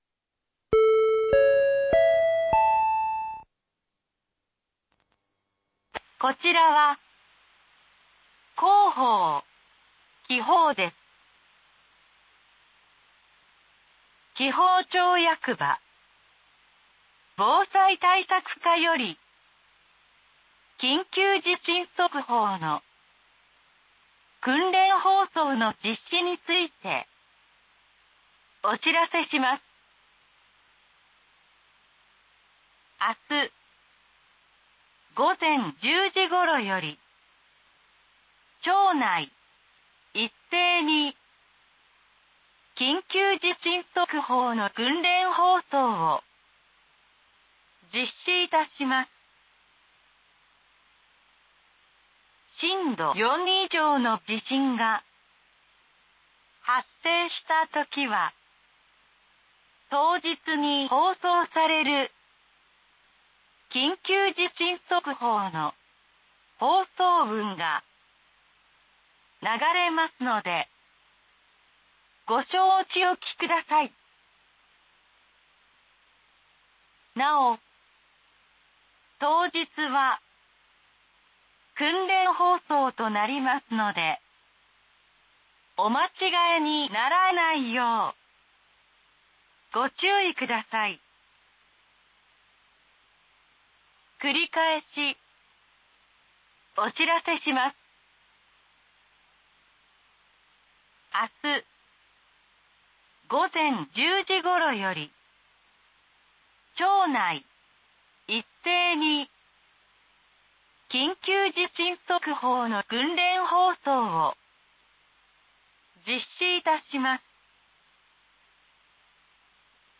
明日、午前１０時頃より、町内一斉に緊急地震速報の訓練放送を実施いたします。 震度４以上の地震が発生したときは、当日に放送される緊急地震速報の放送文が流れますので、ご承知おきください。